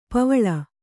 ♪ pavaḷa